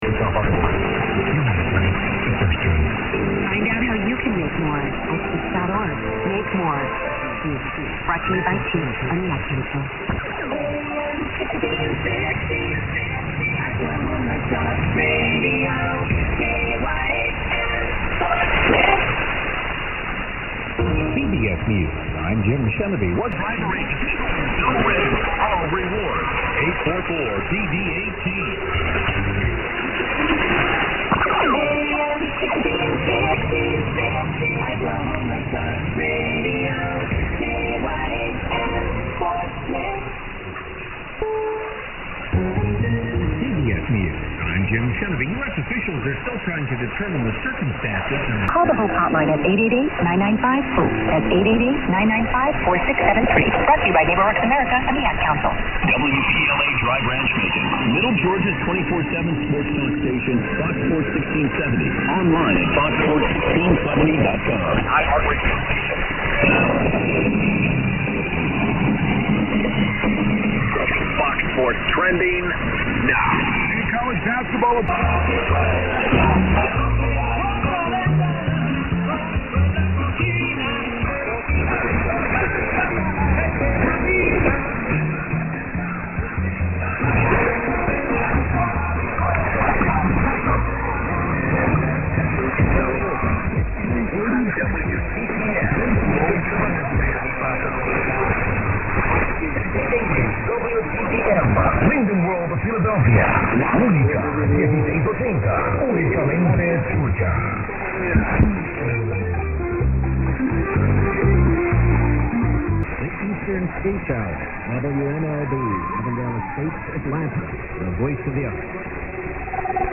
For the first time in a while I am actually hearing stations on MW.